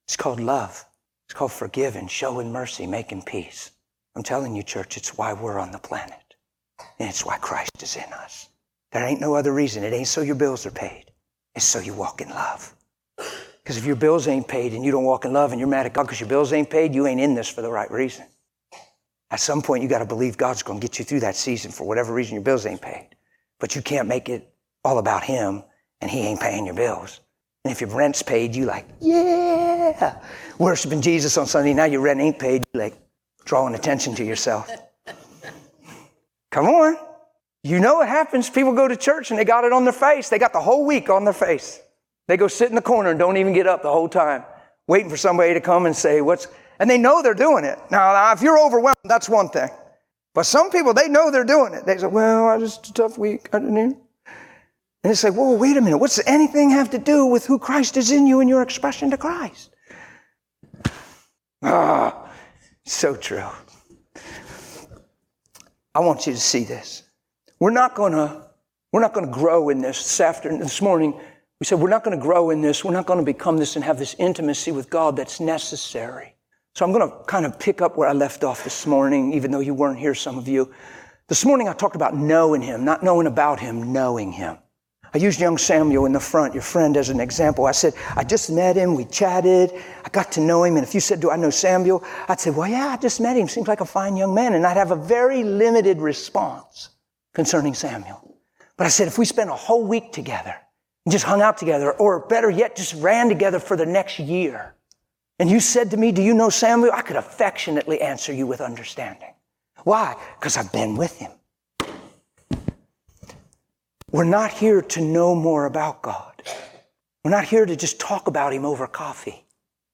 Service Type: Conference